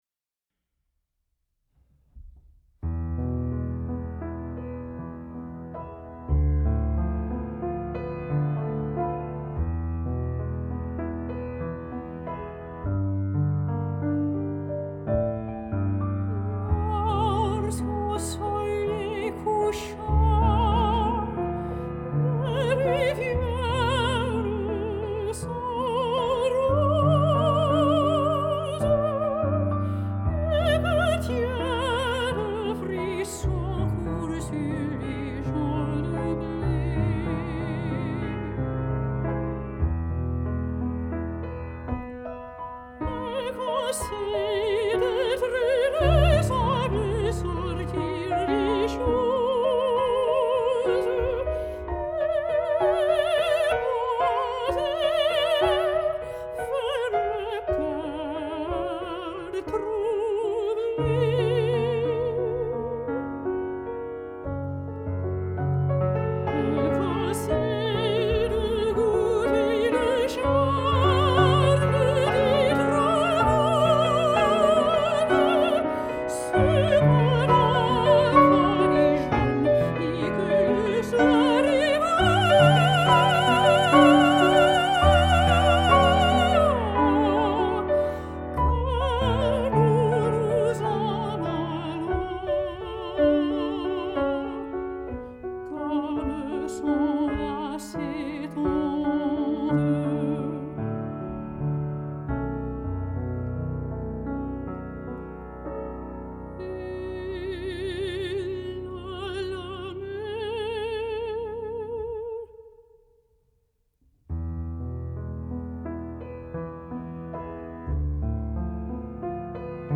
Piano Accompanying – Art Song and Musical Theater
Classical Repertoire